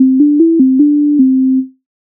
до-ре-мі-до-ре-до
MIDI файл завантажено в тональності C-dur